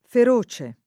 fere] agg. — sim. i cogn. Feroce, Feroci — domin. a Roma l’uso di -o- aperto (d’accordo con la formaz. dòtta della parola, dal lat. ferox -ocis); solo -o- chiuso in Tosc. almeno dal ’700 (per vecchia analogia con altre parole in -oce), come per veloce, con una tendenza inoltre a uguale chiusura nell’astratto ferocia — cfr. precoce